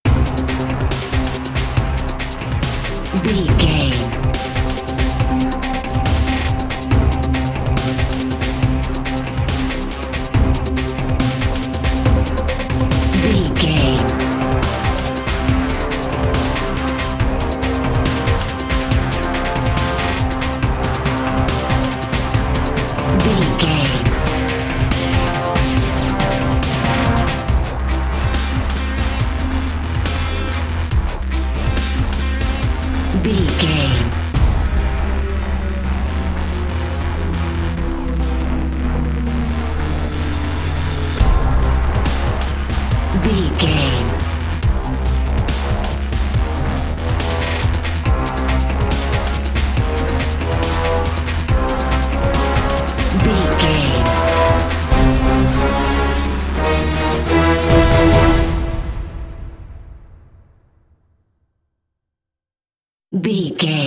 Aeolian/Minor
strings
drums
synthesiser
brass
orchestral
orchestral hybrid
dubstep
aggressive
energetic
intense
synth effects
wobbles
driving drum beat
epic